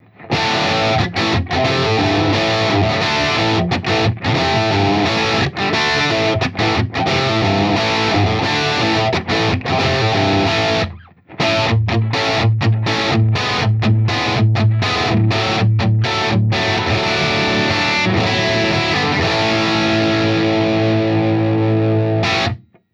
Les Paul (Gibson 57 pickups), into a Republic Amp (My Custom 50watt) into a 1976 Marshall 4x12 with the original Greenback Celestion 25watt speakers.
Audix D1 mic into a Great River MP-2NV preamp with the EQ-2NV EQ (All bands off, HP set to 33Hz) into a 1981 Ashly SC55 into a Apogee AD16 A/D converter.
Room mics are a stereo pair of sE5 mics into my old 8ch Altec mic pre into a Ashly CL-50 compressor, into the Apogee AD-16 A/D converter.